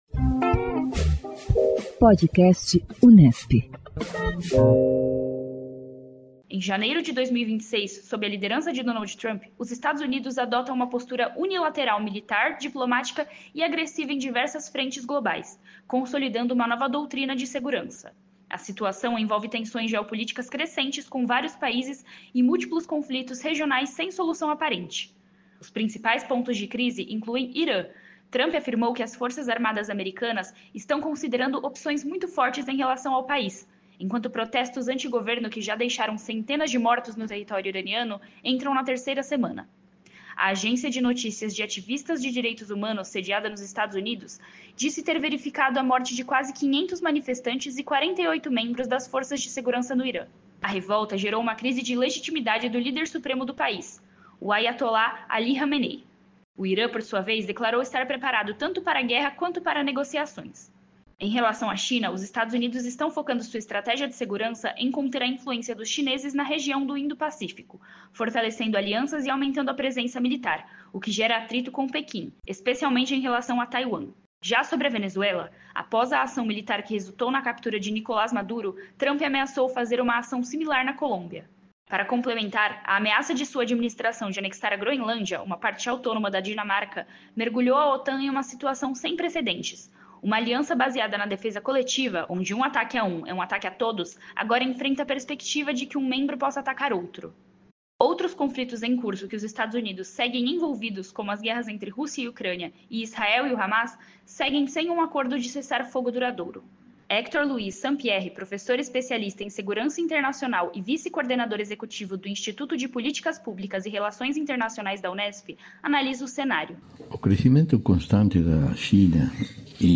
O Podcast Unesp divulga semanalmente entrevistas com cientistas políticos sobre as mais variadas pautas que englobam o universo político e as relações internacionais.